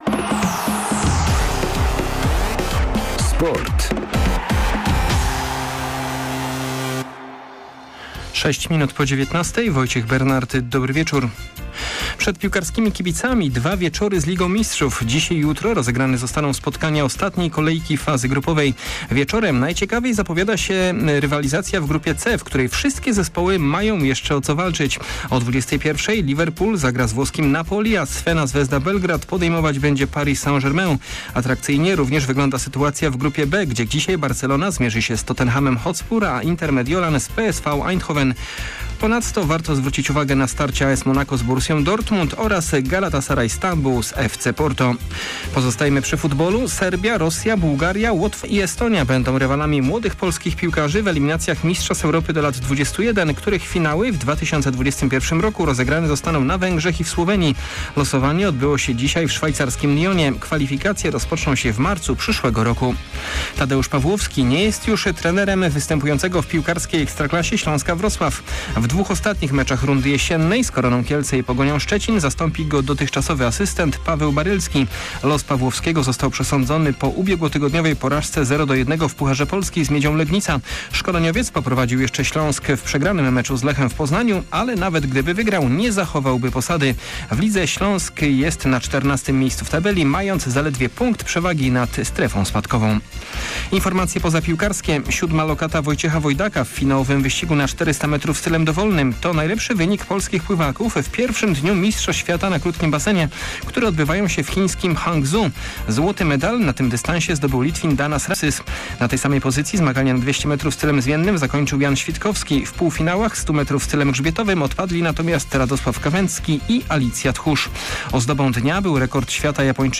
11.12. SERWIS SPORTOWY GODZ. 19:05